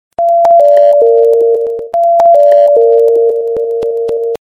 Скачать. Оповещение СМС. Сигнал на вокзале. mp3 звук
Категория: Живые звуки, имитация